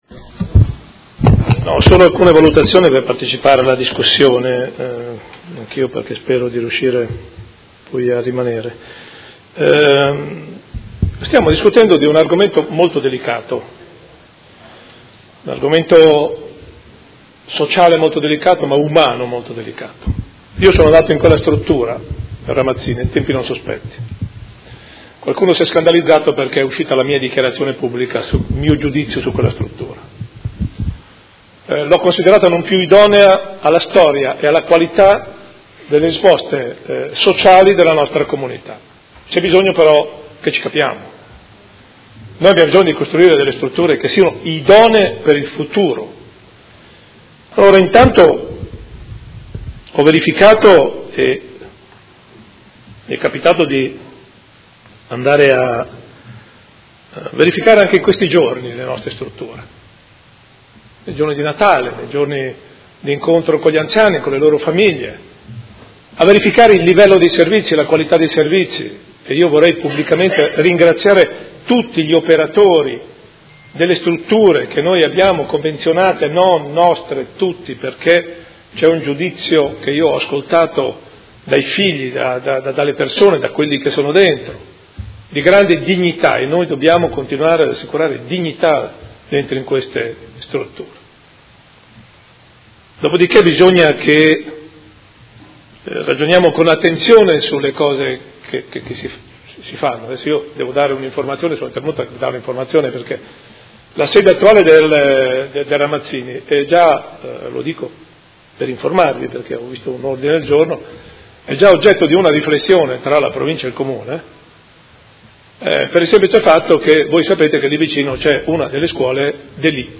Sindaco